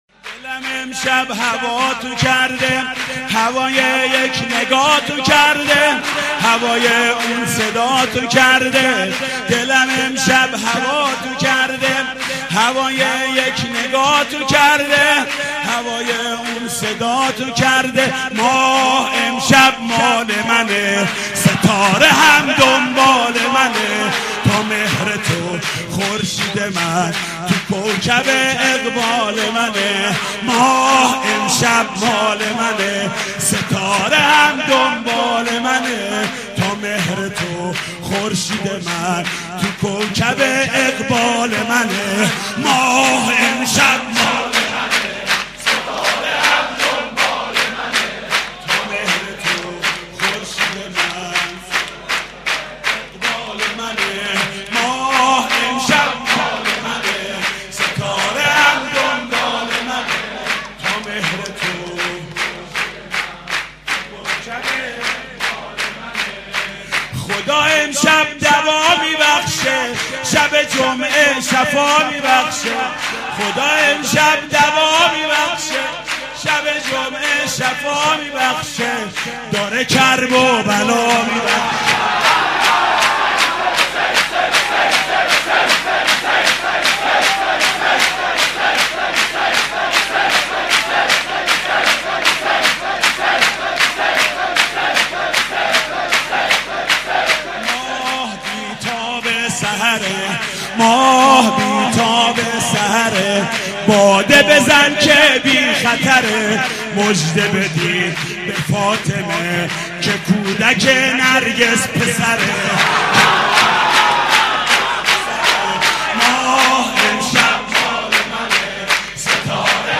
سرود: دلم امشب هواتو کرده